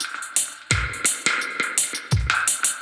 Index of /musicradar/dub-designer-samples/85bpm/Beats
DD_BeatC_85-02.wav